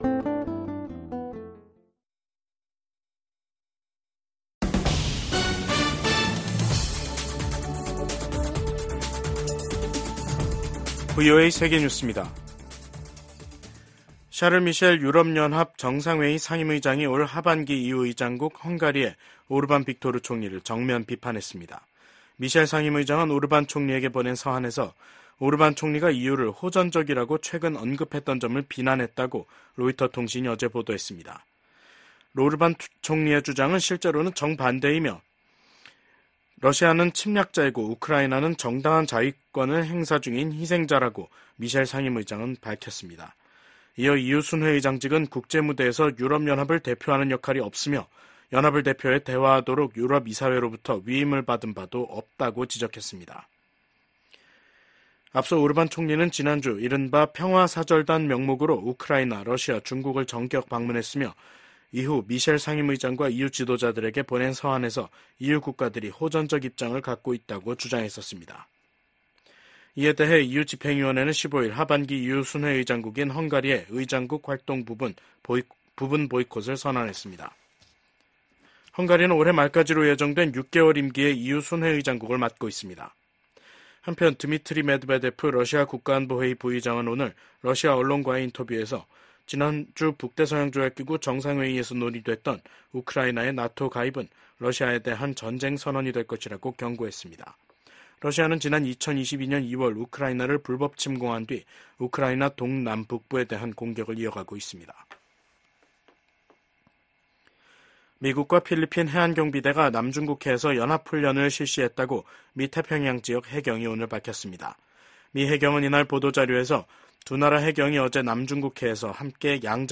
VOA 한국어 간판 뉴스 프로그램 '뉴스 투데이', 2024년 7월 17일 2부 방송입니다. 미 국방부의 고위 관리가 VOA와의 단독 인터뷰에서 미한 양국은 핵을 기반으로 한 동맹이라고 밝혔습니다. 북한의 엘리트 계층인 외교관들의 한국 망명이 이어지고 있습니다. 북한에서 강제노동이 광범위하게 제도화돼 있으며 일부는 반인도 범죄인 노예화에 해당할 수 있다고 유엔이 지적했습니다.